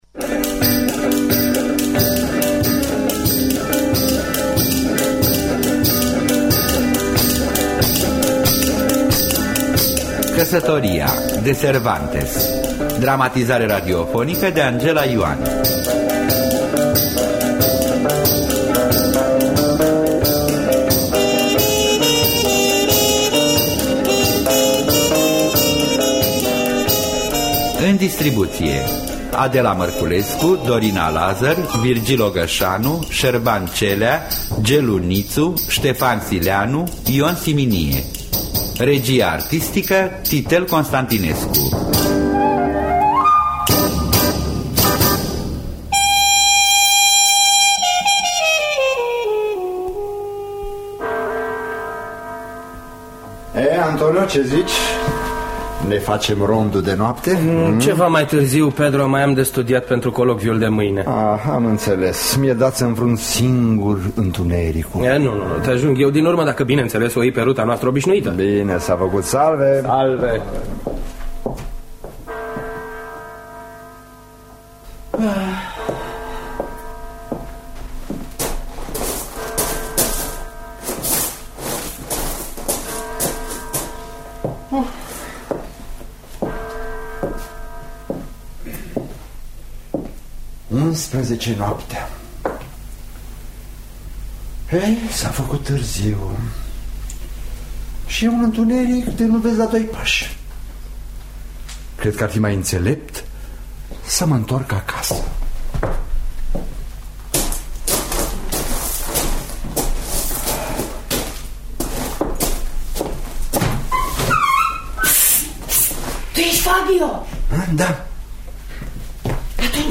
Căsătoria de Miguel Cervantes – Teatru Radiofonic Online